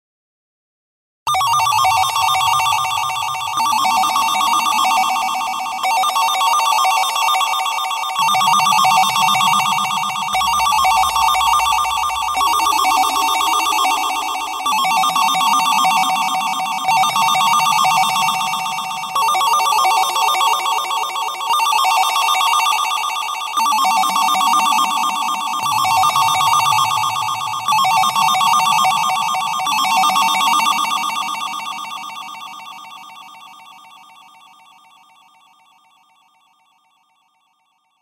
• Качество: 128, Stereo
громкие
электронная музыка
Electronica
космическая музыка